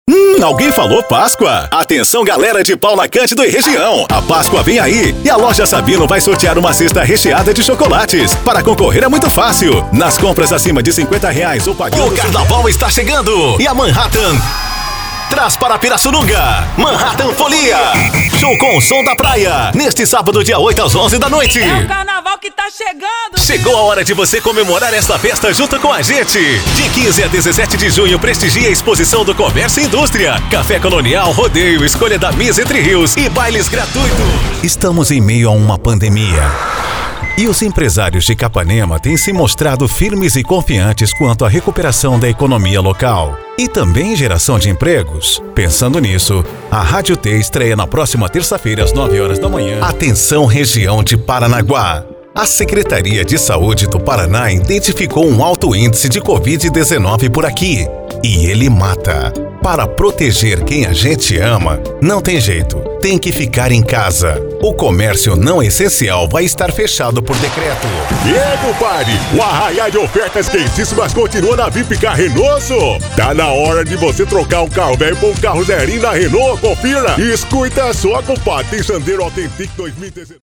VOZES MASCULINAS
Estilos: Padrão Motivacional